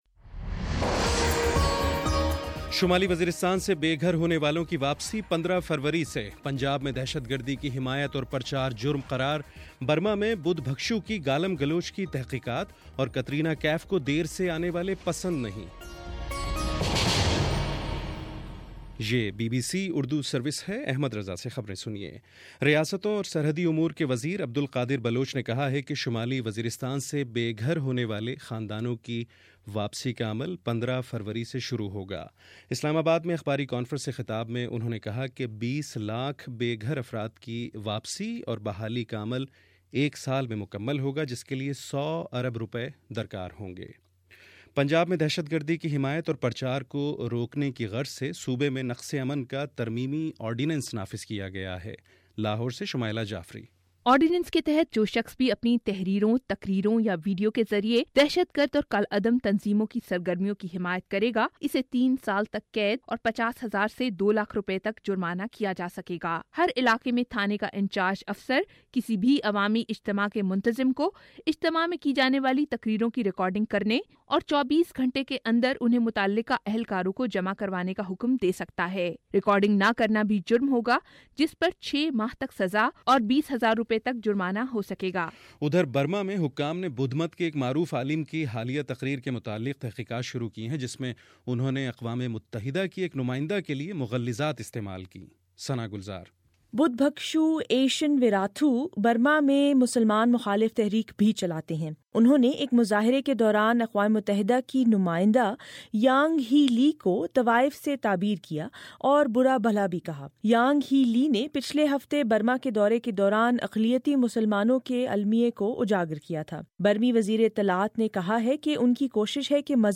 جنوری 21: شام چھ بجے کا نیوز بُلیٹن